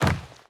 Wood Land.wav